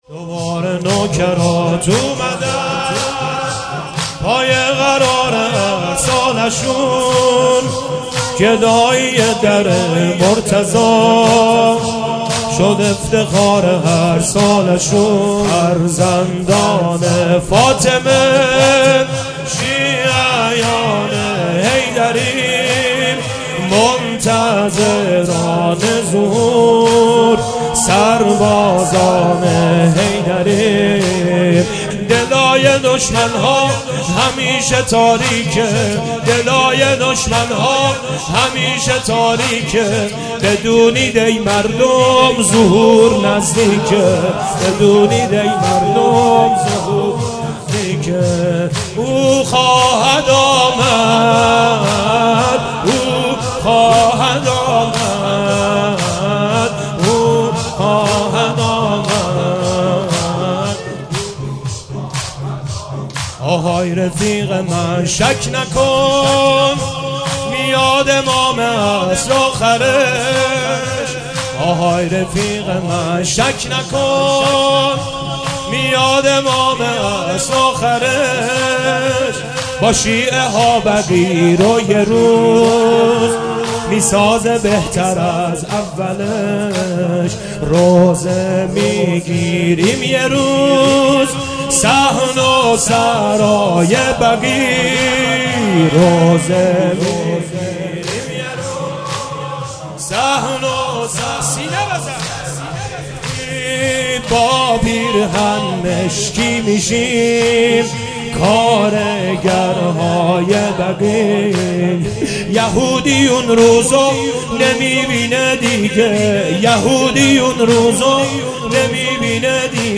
مداحی جدید